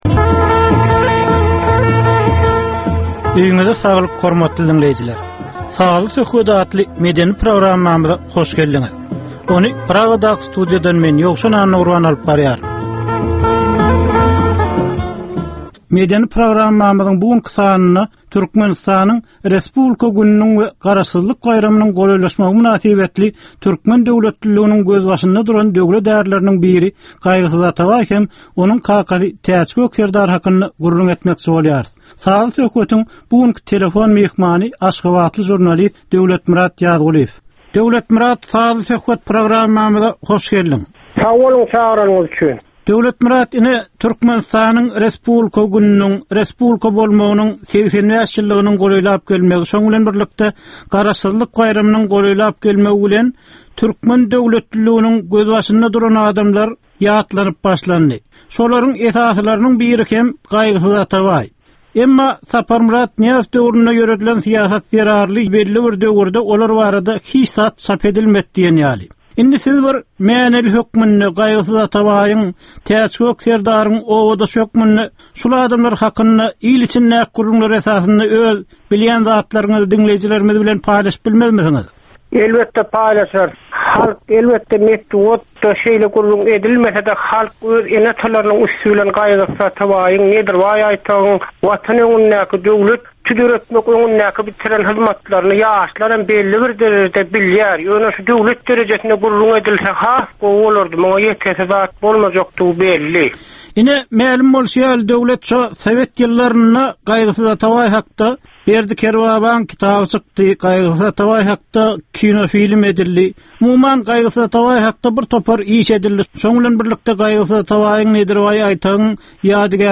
Türkmeniň käbir aktual meseleleri barada 30 minutlyk sazly-informasion programma.